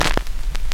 乙烯基 " 乙烯基表面噪音01
描述：记录表面噪音的短暂爆发，就像妈妈过去做的那样。
Tag: 复古 葡萄酒 LP 乙烯基 转盘 记录 裂纹 表面噪声 专辑